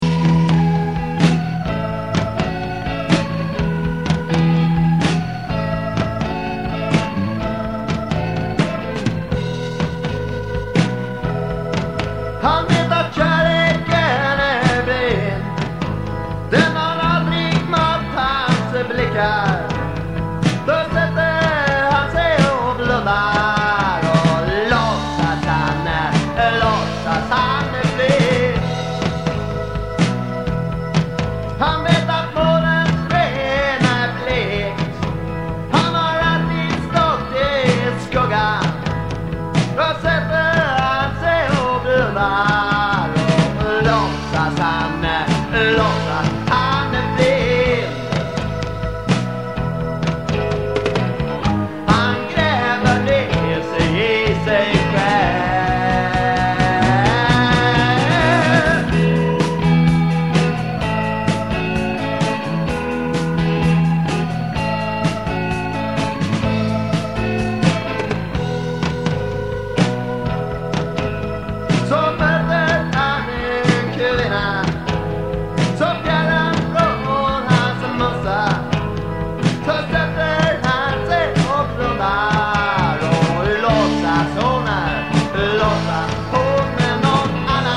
(CTR studio)
Bass
Guitar, vocal
Organ, piano, vocal